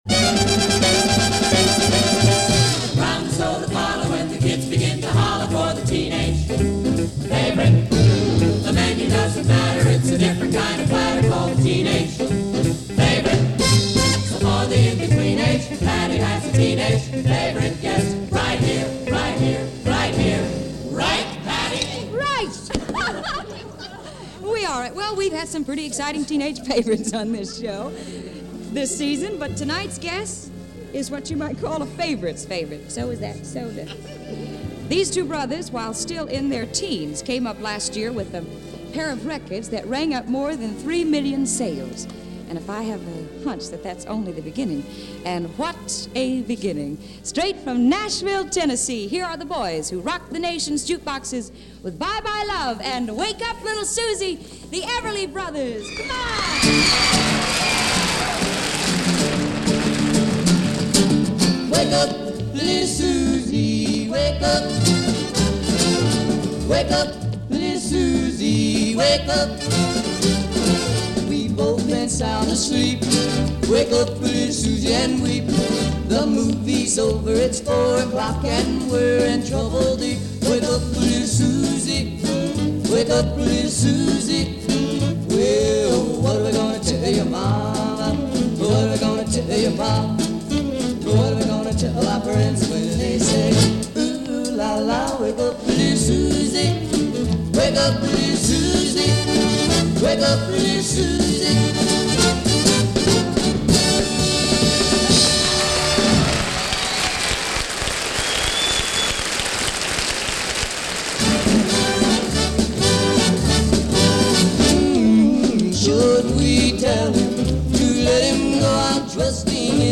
synonymous with the Golden Age of Rock n' Roll.